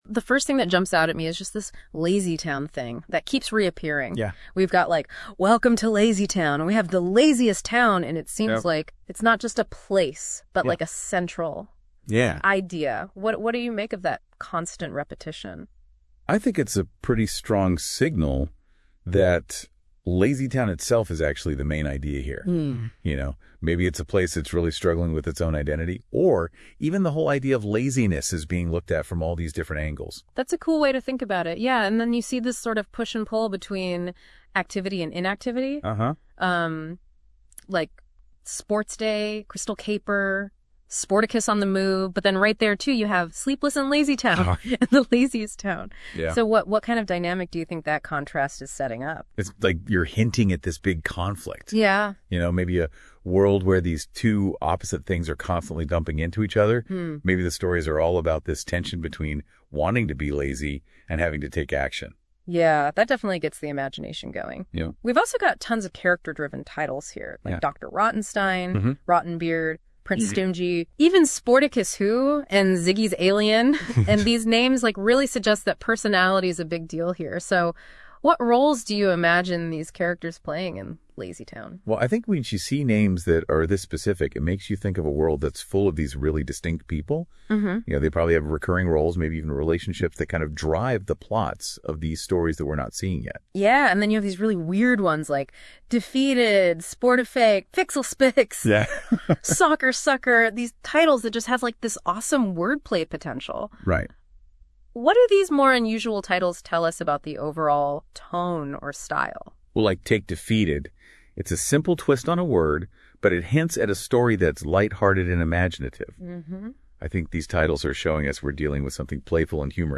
Although what's funny in the second file is that from about 1:35 to 2:00, the man is explaining the tech to the woman, then from there to 2:35 it seems like she's explaining it to him and he's hearing about it for the first time.
It sounds quite real but it's not perfect yet.